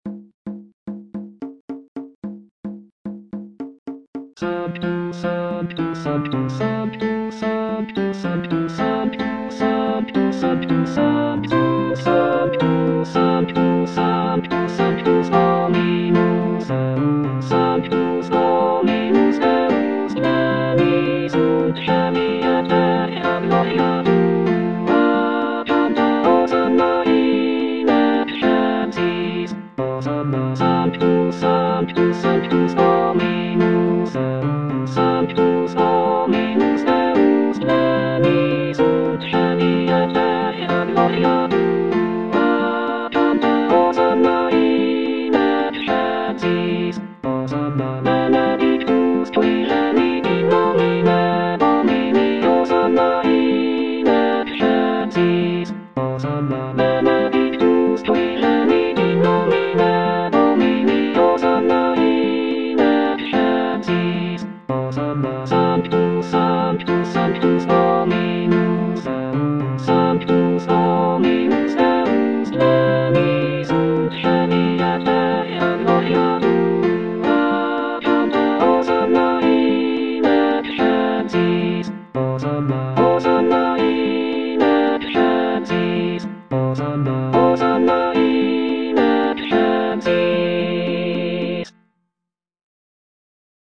(All voices) Ads stop